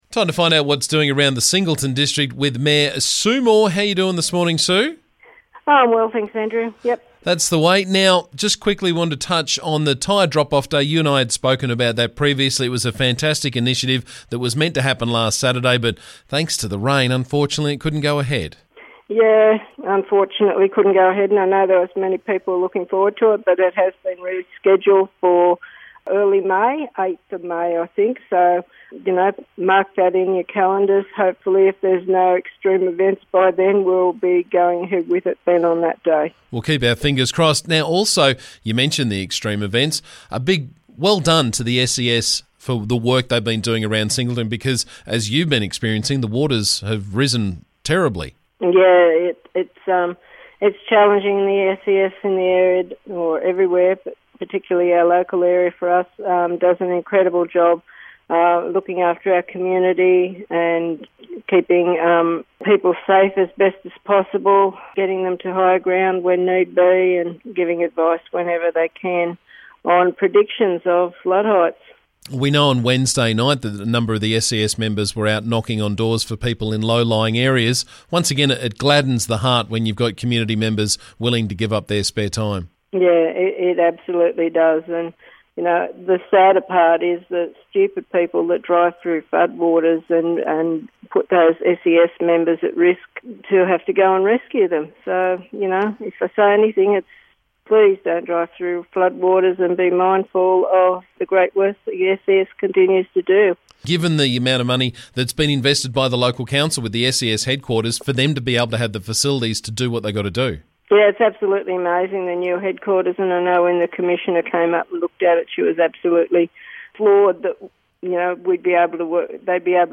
Every couple of weeks we catch up with Singleton Council Mayor Sue Moore to find out what's happening around the district.